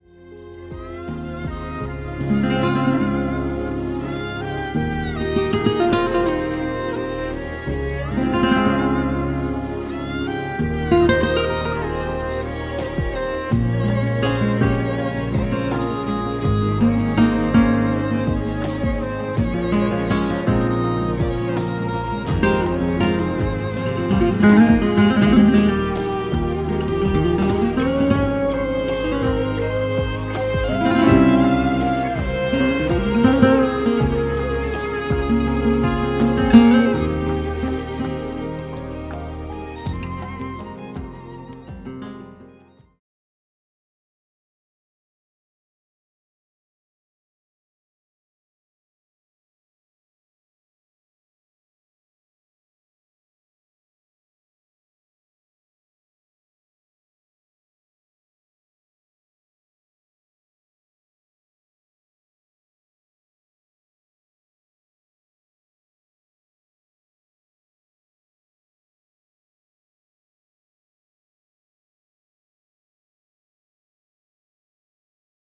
Guitar for Soundtrack- bollywood movie